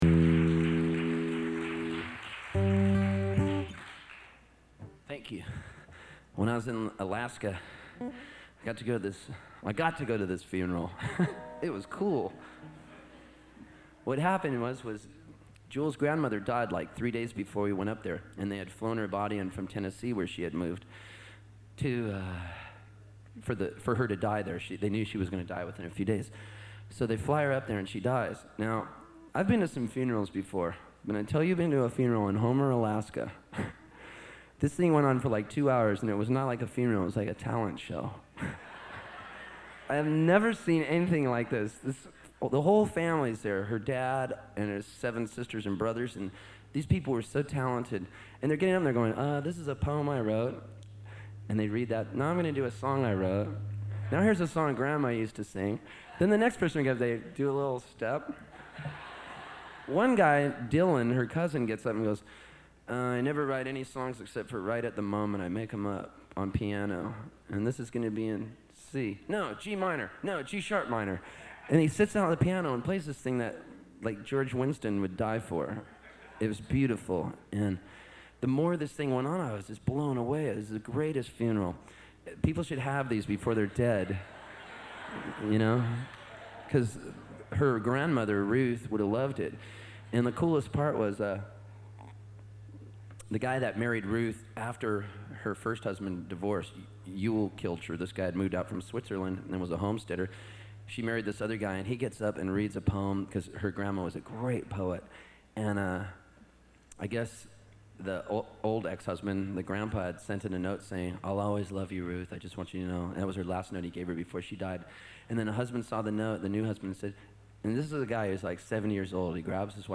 Index of /audio/Steve_Poltz/Java_Joe's_-_1997-07-26
07_-_Jewel's_Grandmother's_Funeral_ Story.mp3